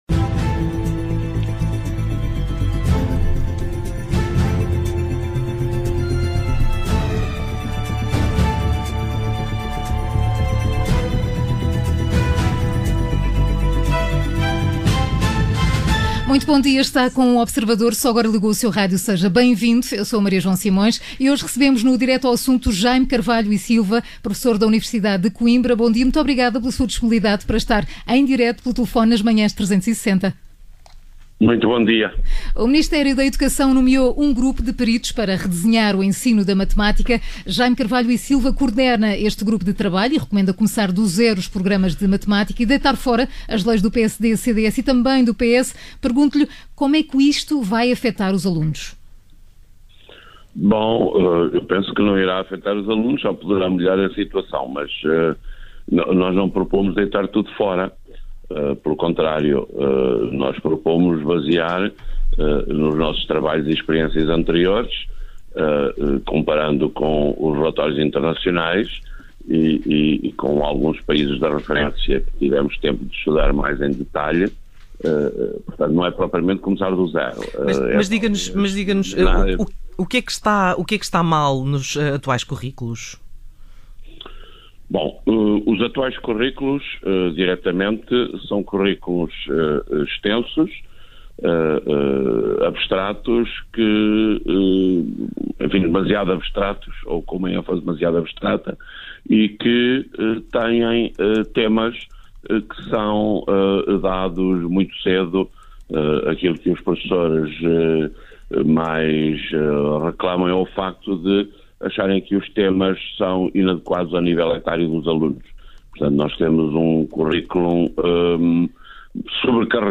Entrevistado em direto nas manhãs 360 da Rádio Observador.